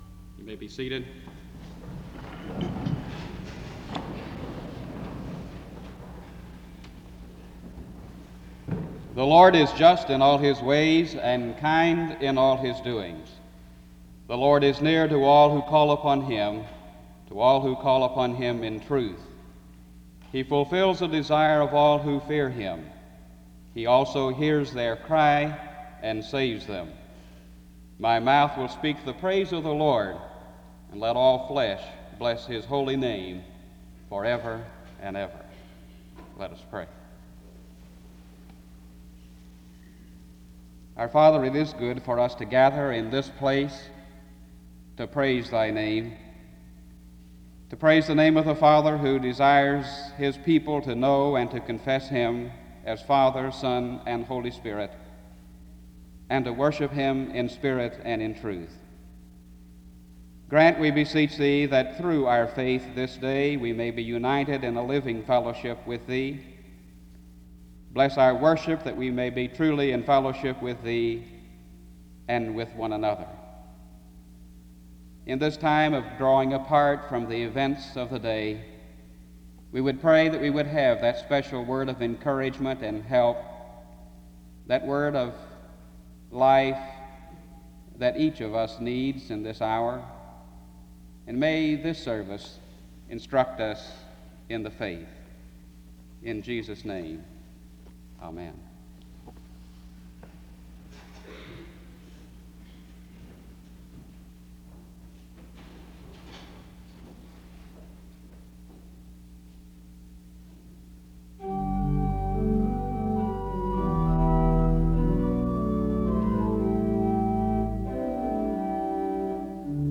The service opens with a scripture reading from 0:00-0:35. A prayer is offered from 0:36-1:38. Music plays from 1:50-3:44.